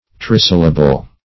Search Result for " trisyllable" : Wordnet 3.0 NOUN (1) 1. a word having three syllables ; The Collaborative International Dictionary of English v.0.48: Trisyllable \Tri*syl"la*ble\, n. [Pref. tri- + syllable.]